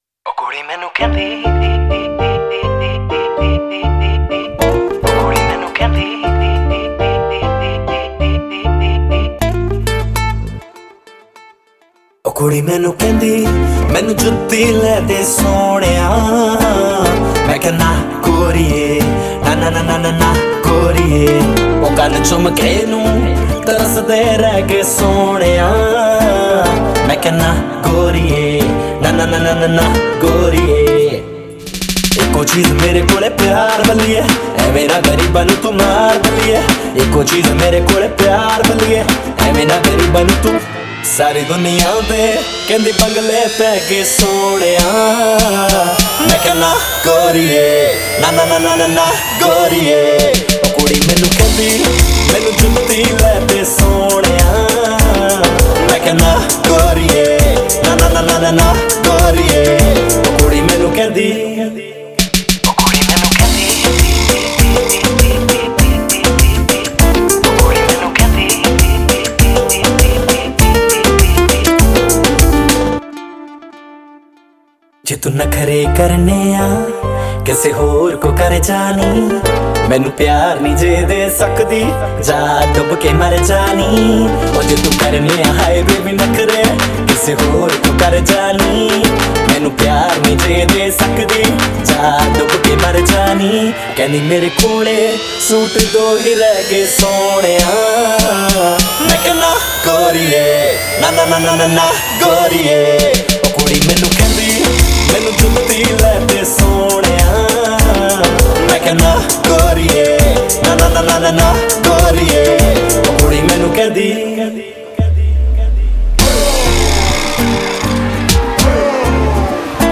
Punjabi Music Album